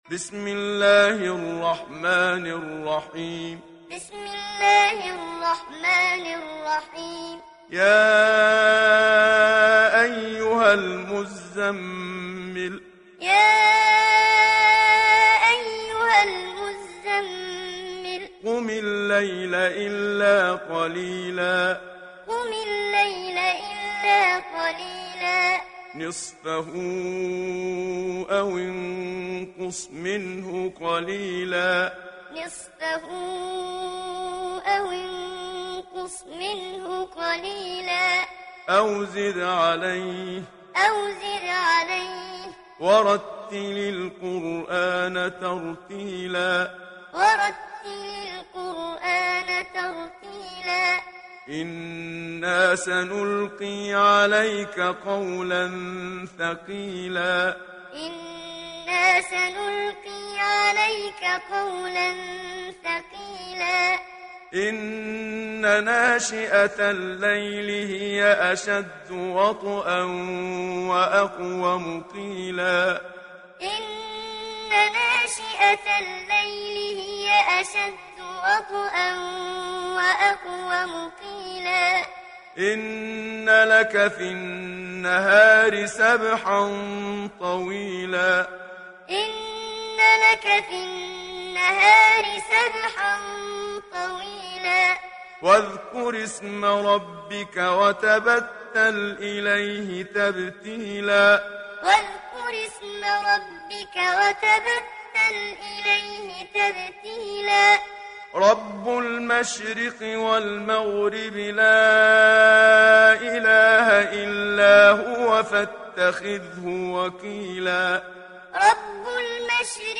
تحميل سورة المزمل mp3 بصوت محمد صديق المنشاوي معلم برواية حفص عن عاصم, تحميل استماع القرآن الكريم على الجوال mp3 كاملا بروابط مباشرة وسريعة
تحميل سورة المزمل محمد صديق المنشاوي معلم